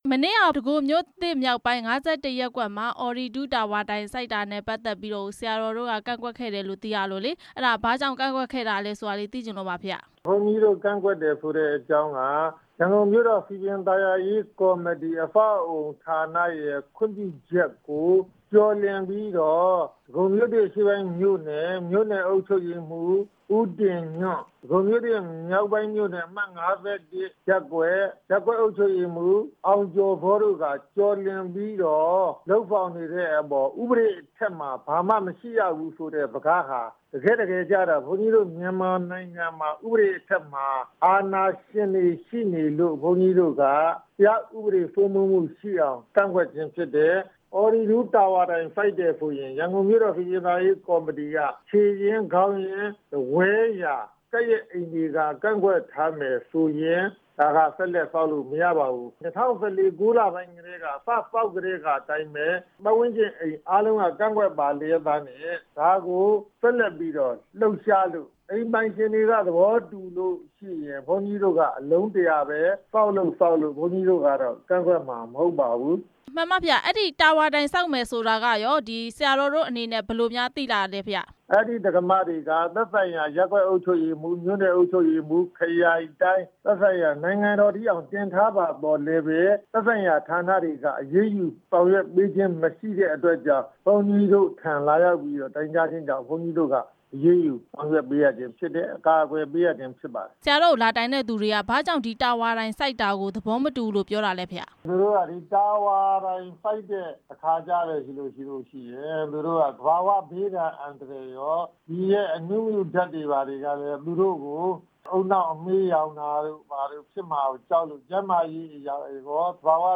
အော်ရီဒူး ဆက်သွယ်ရေးတာဝါ တိုင် စိုက်ထူတာကို ကန့်ကွက်တဲ့ အကြောင်း မေးမြန်းချက်